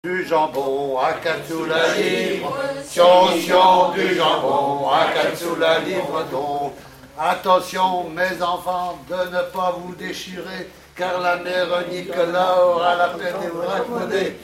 L'enfance - Enfantines - rondes et jeux
Pièce musicale inédite